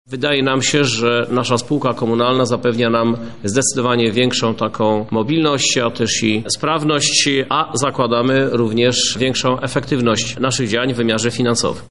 -Cmentarz wymaga większych środków ze względu na rozbudowę i innego sposobu zarządzania – tłumaczy Krzysztof Żuk, prezydent Lublina